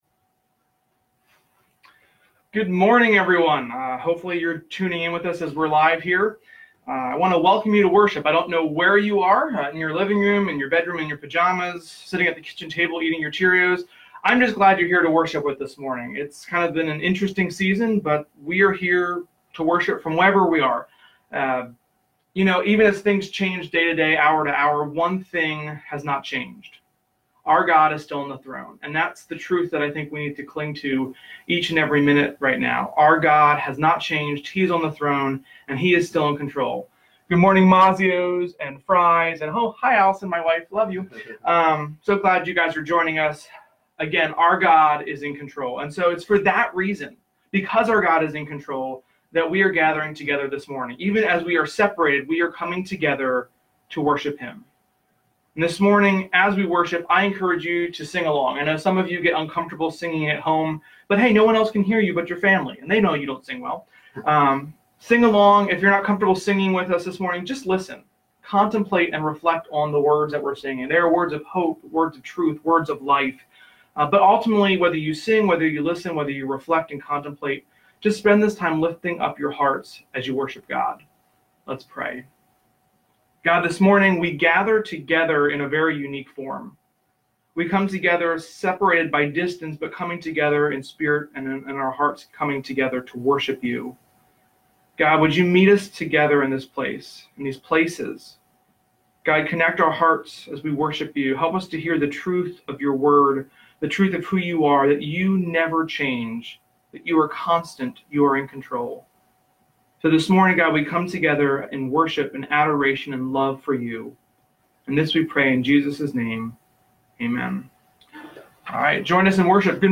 HBIC-Church-was-live.-online-audio-converter.com_.mp3